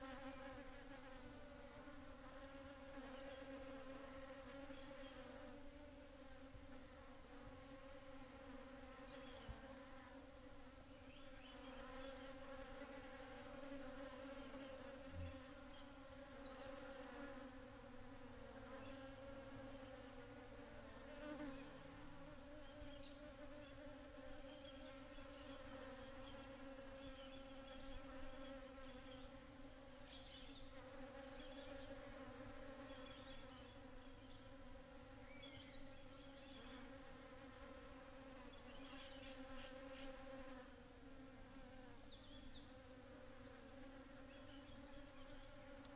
Brazil selva sounds " Mineiros Brasil selva 10.12.17 morning bees swarm 1
描述：当他们在清晨吞下盛开的百慕大草时，蜜蜂的声音嗡嗡作响。
Tag: 嗡嗡声 蜜蜂 昆虫 性质 现场录音 巴西